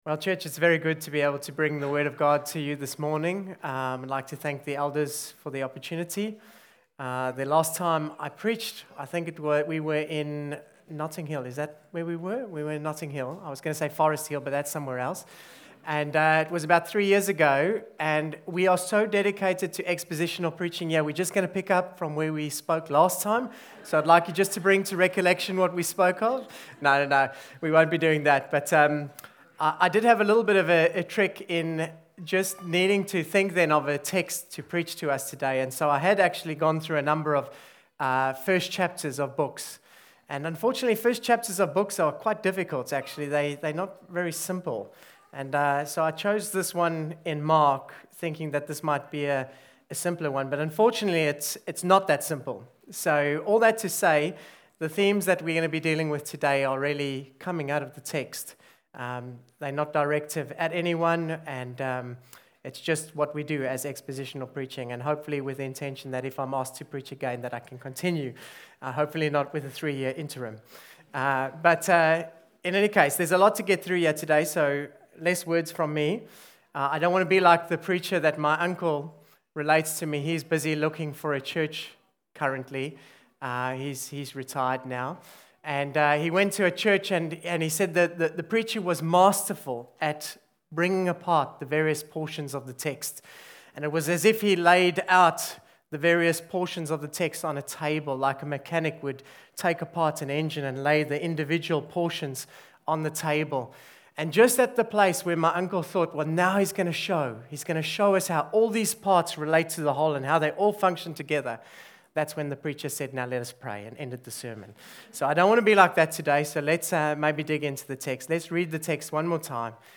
Church-Sermon-141225.mp3